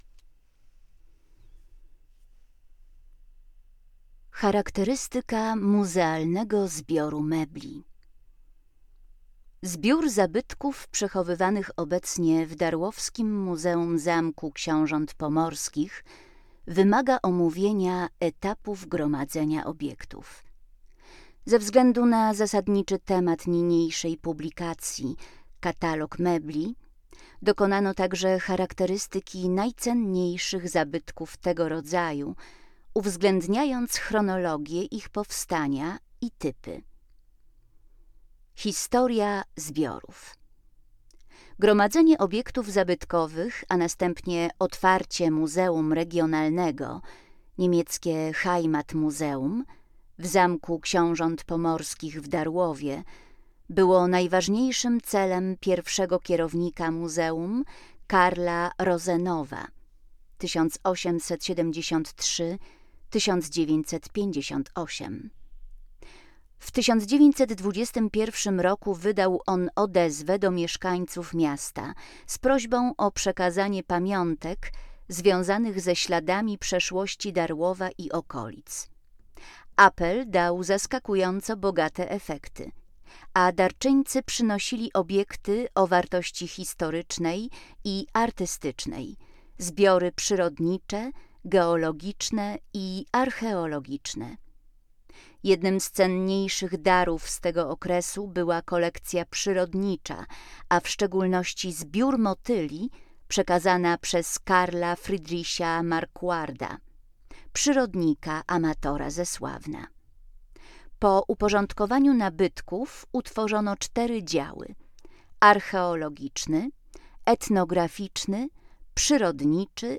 Nagranie wstępu do katalogu mebli ze zbiorów ZKPMwD